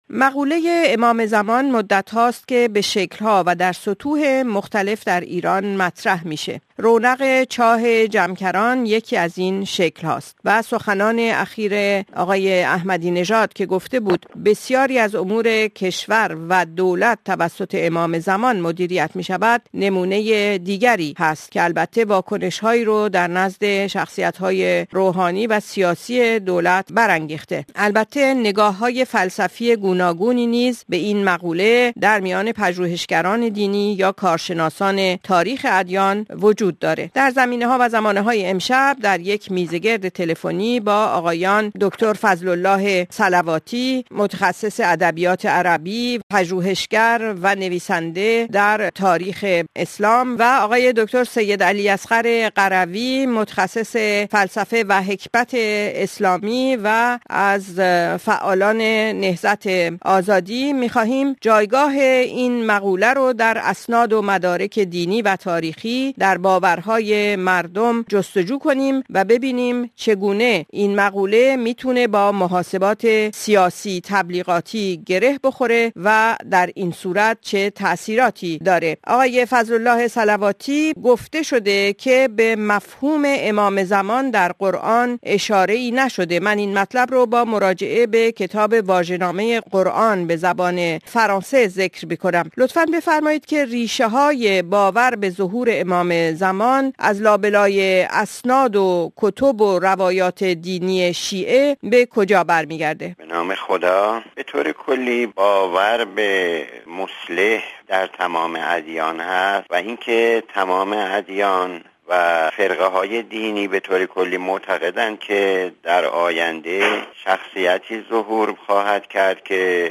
میزگرد رادیوی بین المللی فرانسه